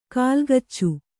♪ kālgaccu